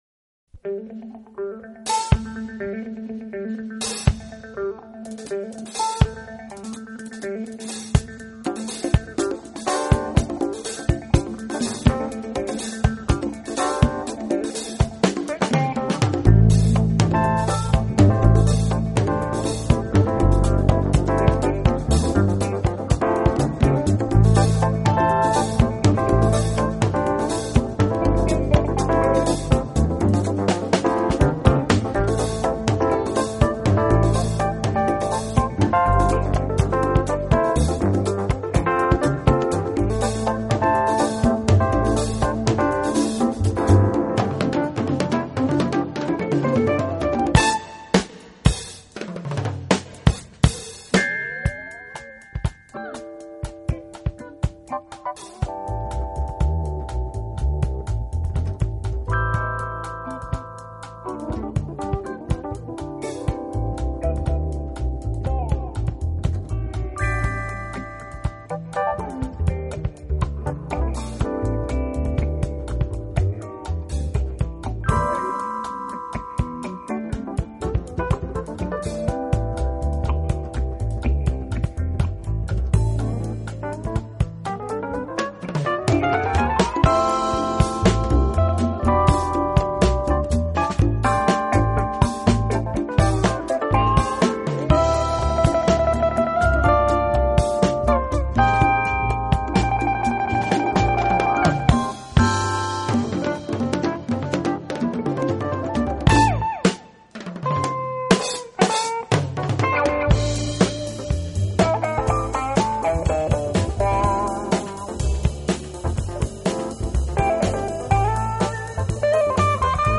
电贝司的每一下拔动都充满弹
性，空气感十足，低频下潜很深，动态明显。